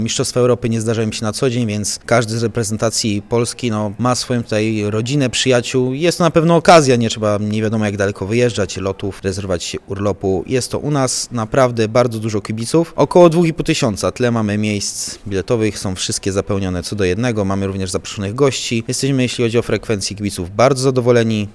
W Arenie Jaskółka odbyły się Mistrzostwa Europy w tej dyscyplinie.